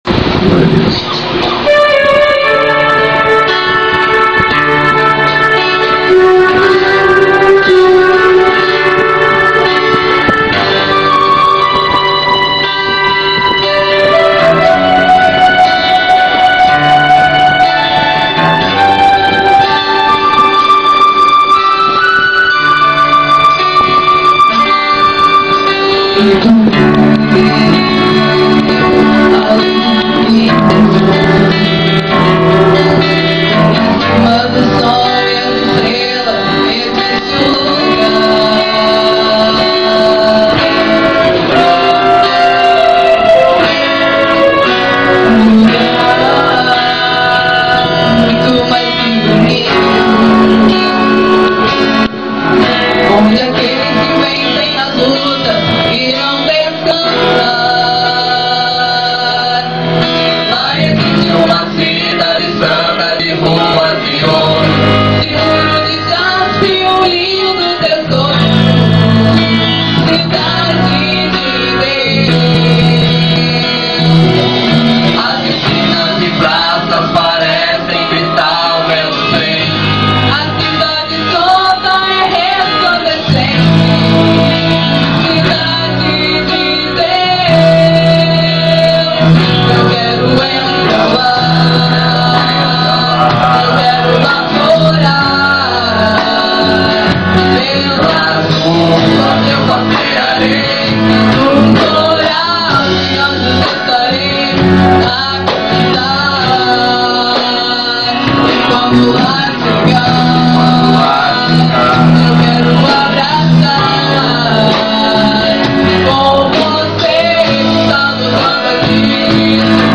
Pedimos desculpas pela qualidade (audio extraido de video).